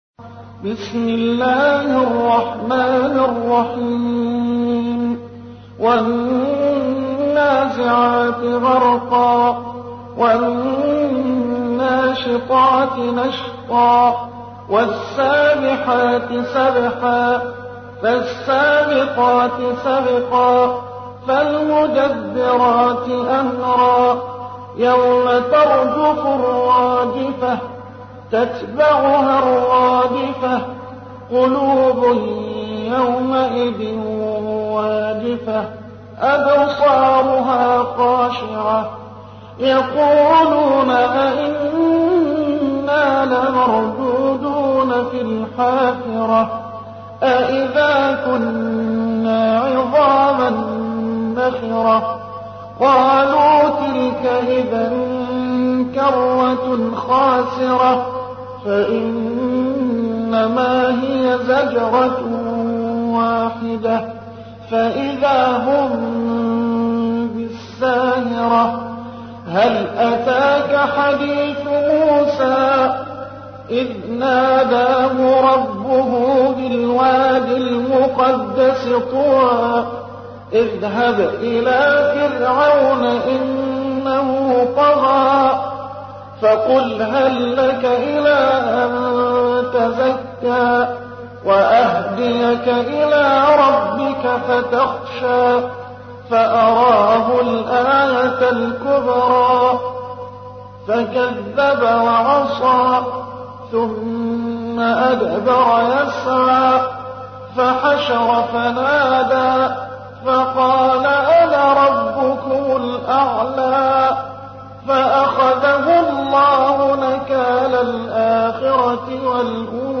تحميل : 79. سورة النازعات / القارئ محمد حسان / القرآن الكريم / موقع يا حسين